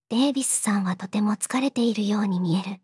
voicevox-voice-corpus / ita-corpus /四国めたん_ヒソヒソ /EMOTION100_003.wav